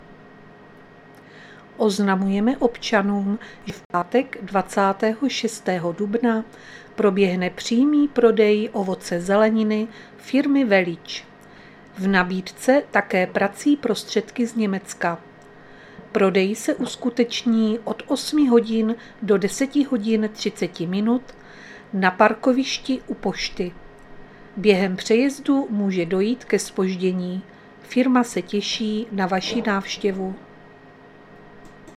Záznam hlášení místního rozhlasu 24.4.2024